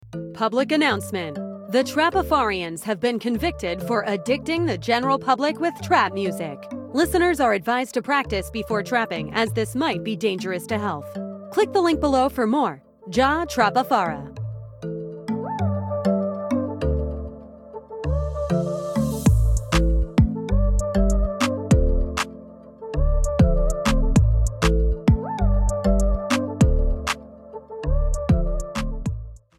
Trap music